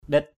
/ɗɪt/